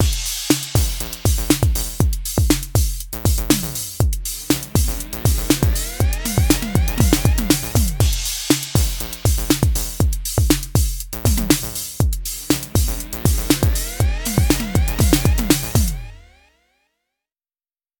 One of the effects was sequenced throughout the drum pattern and another (a longer reverse effect) placed only a few times throughout.
The effects samples in the mix